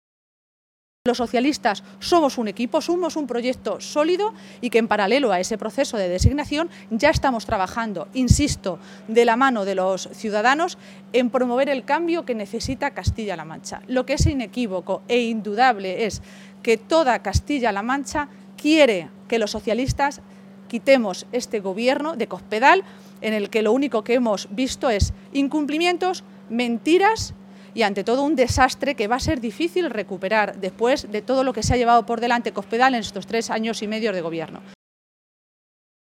Maestre se pronunciaba de esta manera esta tarde, en Toledo, en una comparecencia previa a la reunión de la ejecutiva regional socialista en la que, según ha apuntado, se va a analizar el calendario de todos los procesos internos que debe afrontar a partir de ahora los socialistas.